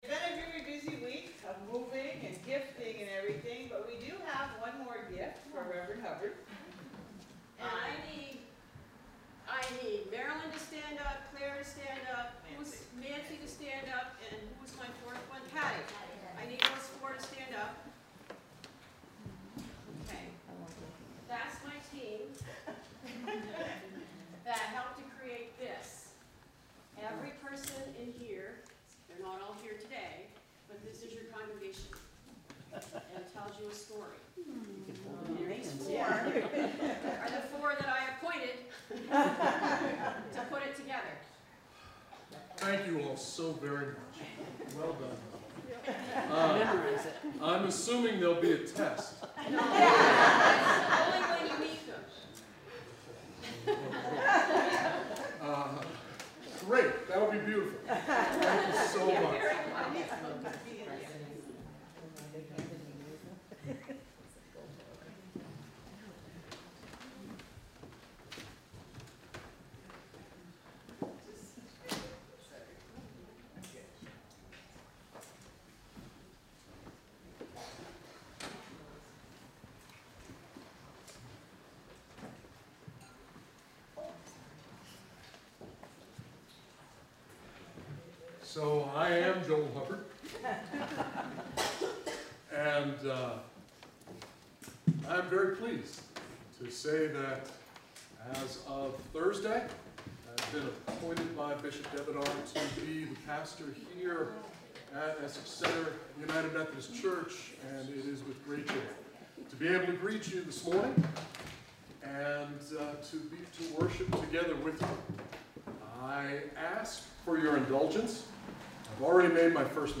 We welcome you to either virtual or in-person worship on Sunday, July 4, 2021 at 10am! You are welcome to join us for in-person worship in the Essex Center UMC sanctuary, you may participate on Facebook Live by clicking here,Read more